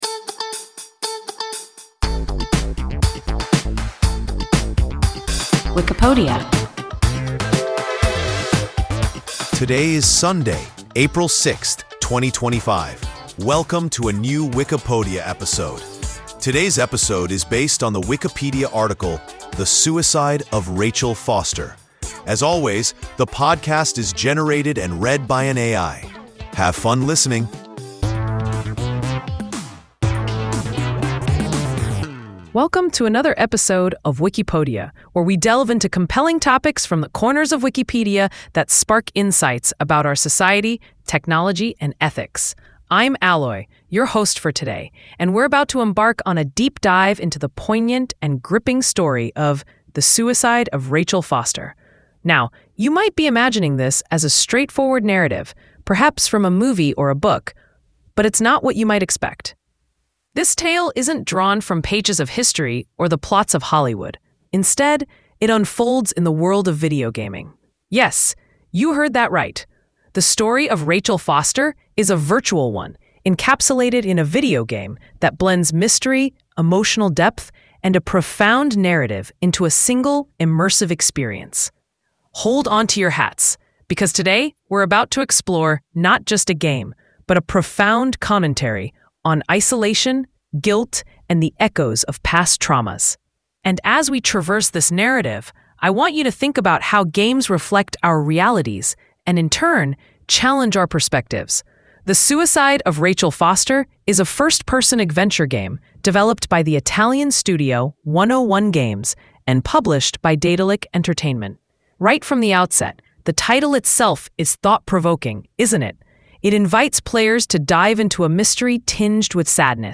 The Suicide of Rachel Foster – WIKIPODIA – ein KI Podcast